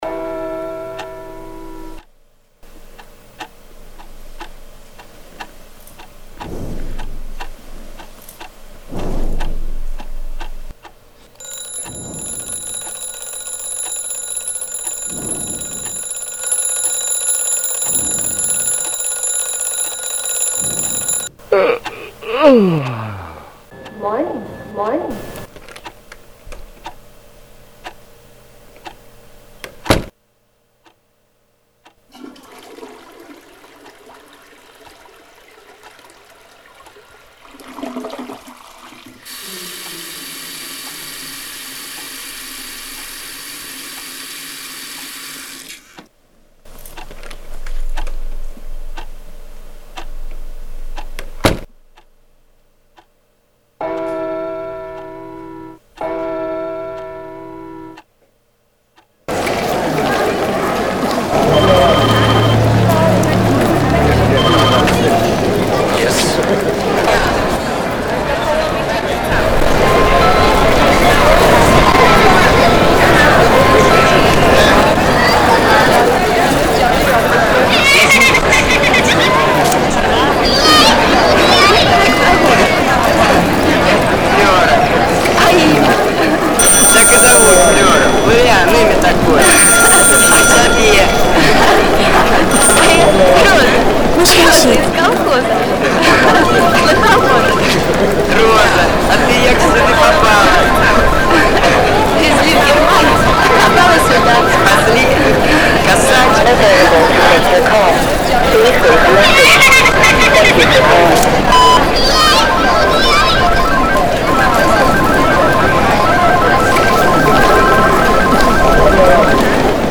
In this project, I tried to express one ordinary day from the morning to the midnight only with sound. there are a lot of sounds around us but only few do we perceive because we get used to those too much.
By using those representivie sounds, I created 3 minite one day which can be divided 3 situations representing silence, complexity and confusion. Each situation is differenciated by layering of sounds.